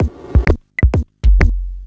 Electrohouse Loop 128 BPM (13).wav